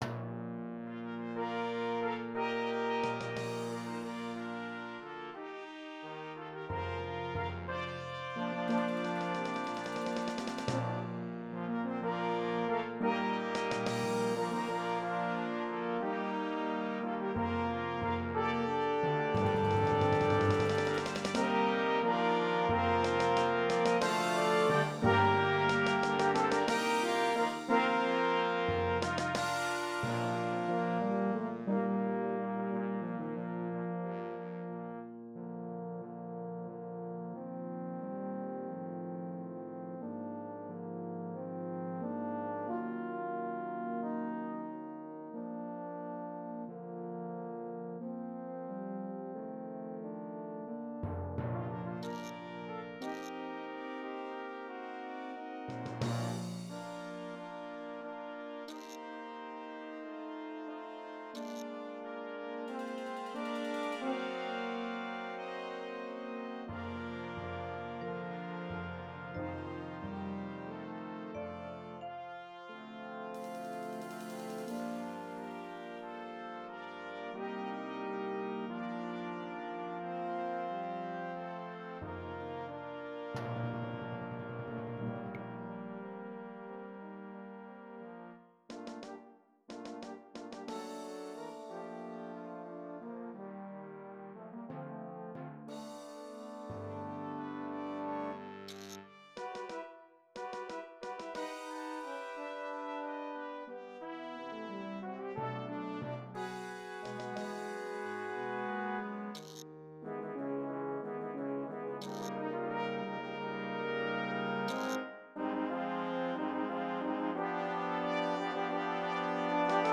Sheet Music for Brass Band